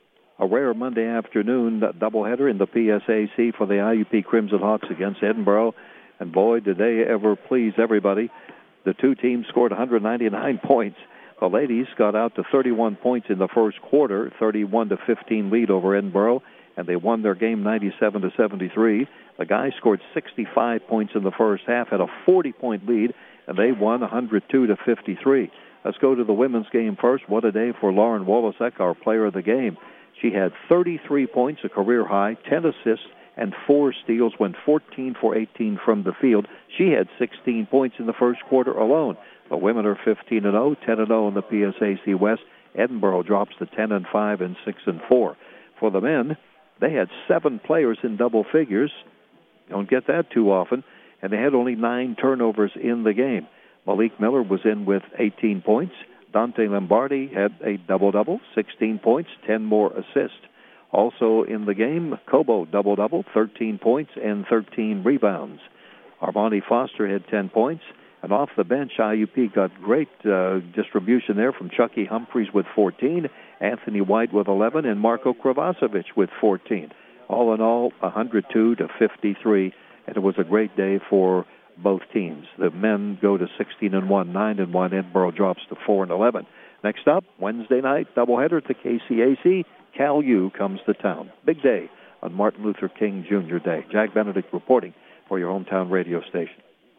Edinboro-at-IUP-Recap.mp3